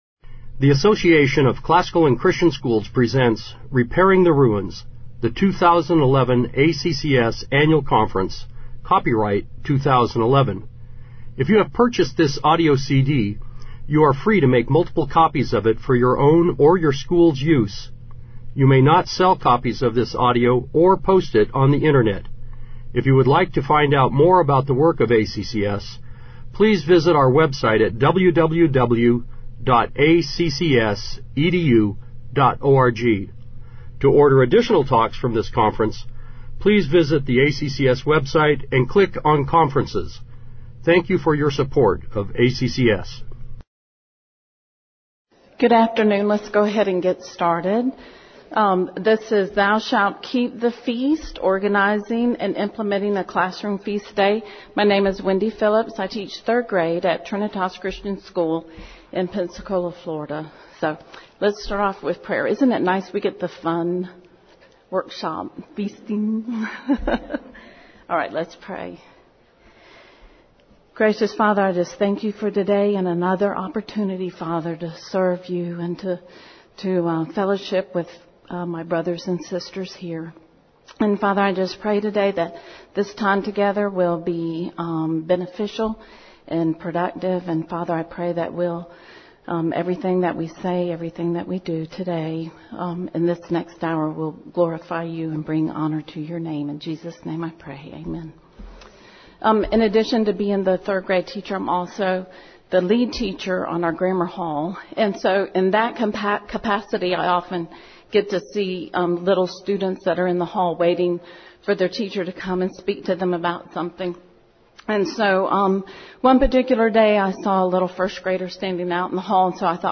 2011 Workshop Talk | 1:01:32 | All Grade Levels, General Classroom
This talk will offer suggestions about how to plan for and succeed at putting on a day full of fun activities for your grammar school class. Among the topics discussed will be organizing the planning process, selecting the projects, utilizing parental support, planning a wardrobe, and keeping the activities centered around the curriculum. Speaker Additional Materials The Association of Classical & Christian Schools presents Repairing the Ruins, the ACCS annual conference, copyright ACCS.